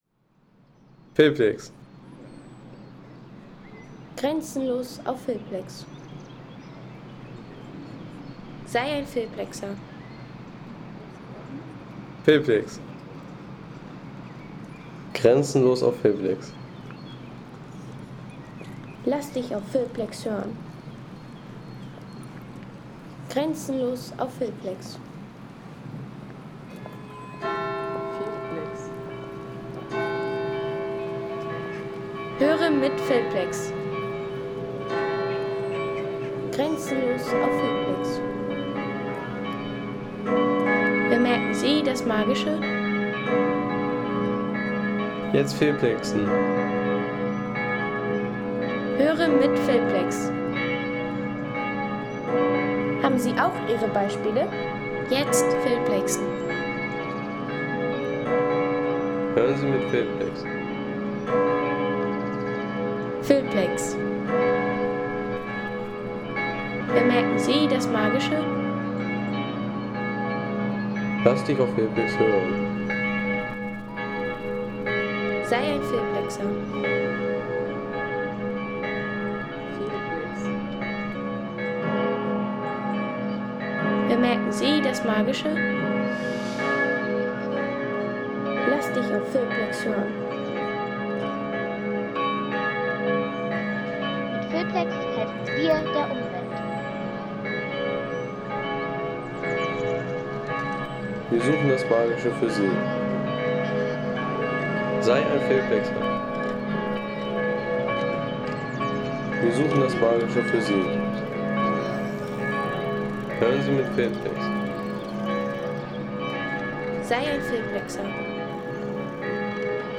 Geläut der Fuldaer Kathedrale vom Domvorplatz